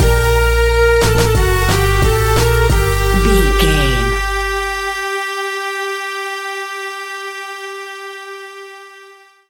Aeolian/Minor
World Music
percussion